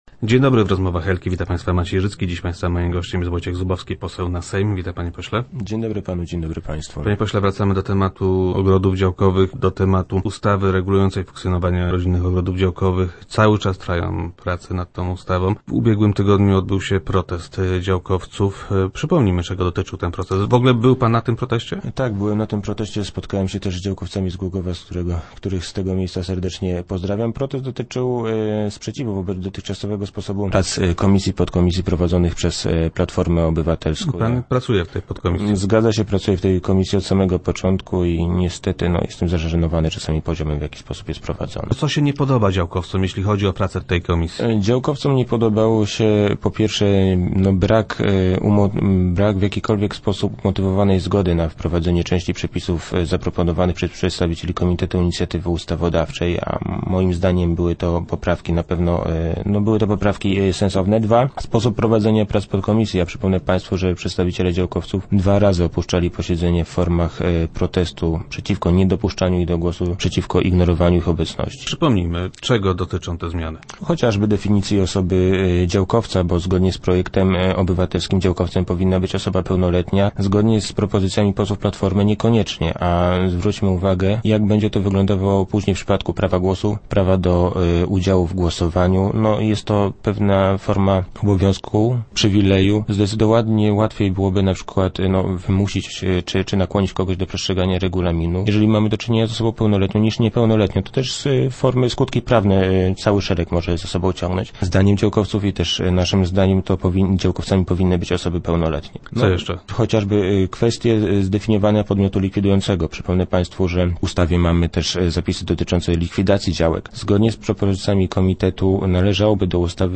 Parlamentarzysta był goście Rozmów Elki.